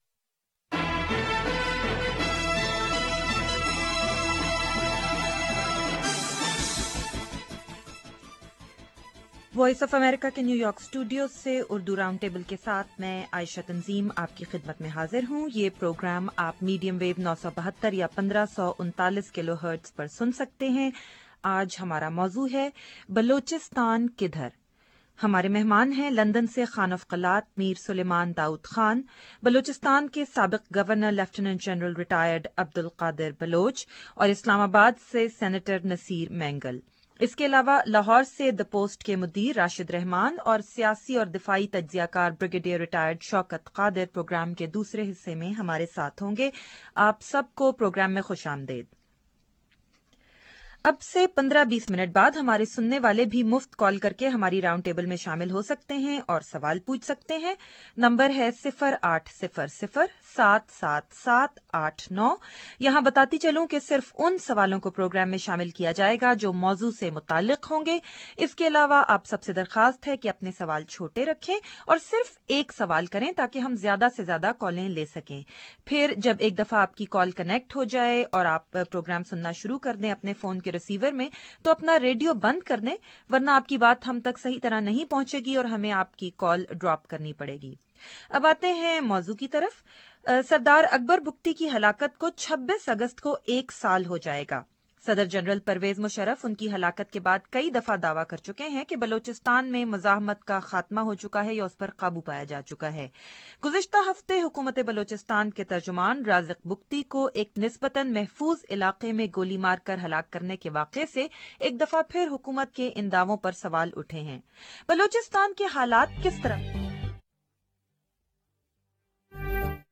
Prince Mir Suleiman Daud interview with Voice fo America